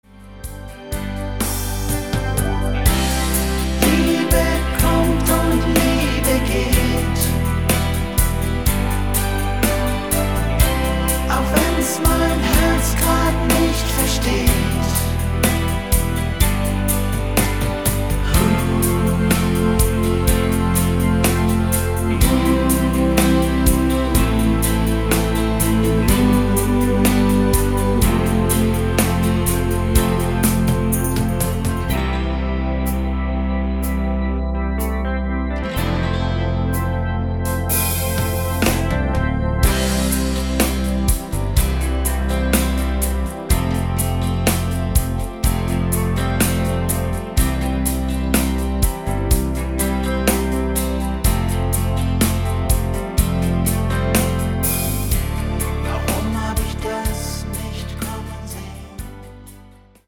Rhythmus  Slow